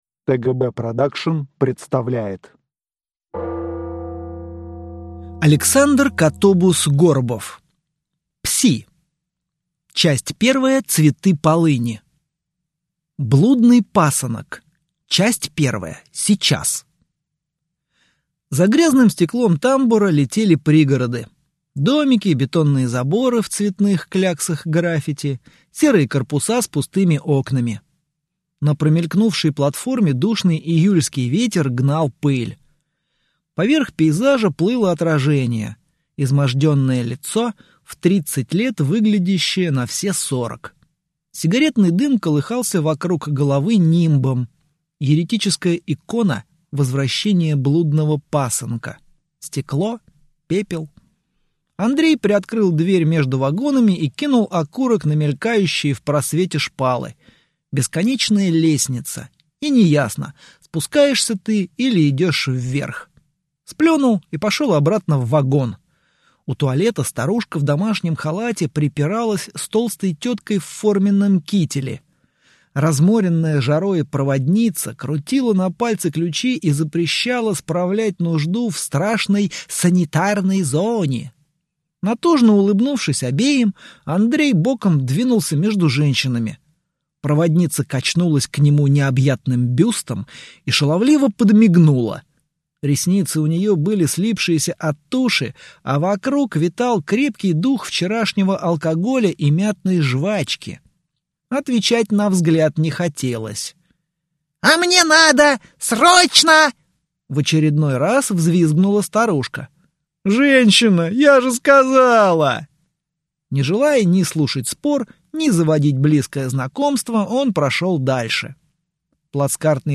Аудиокнига Пси | Библиотека аудиокниг
Прослушать и бесплатно скачать фрагмент аудиокниги